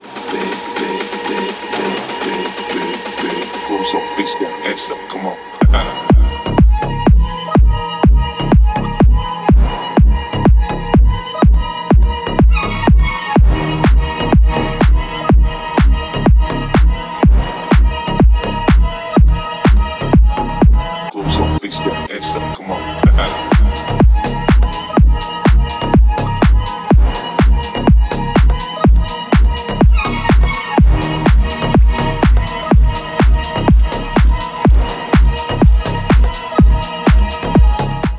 громкие
мощные
remix
клубняк
G-House
клубная музыка
танцевальная музыка